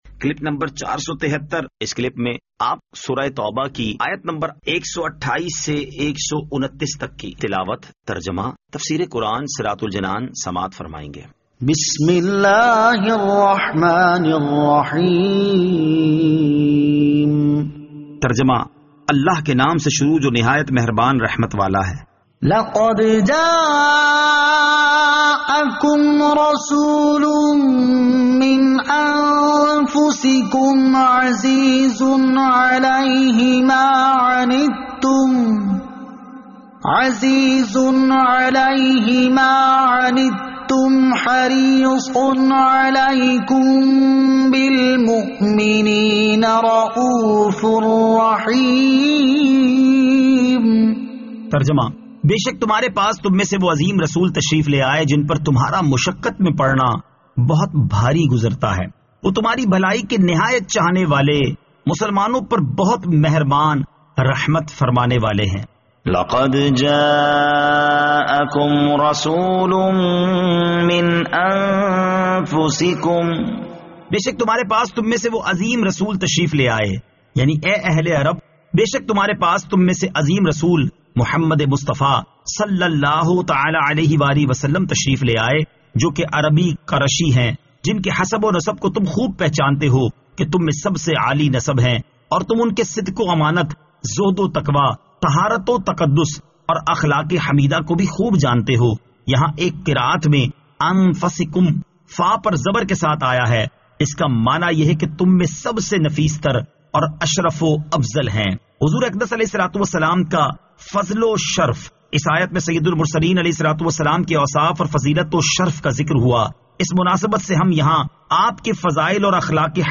Surah At-Tawbah Ayat 128 To 129 Tilawat , Tarjama , Tafseer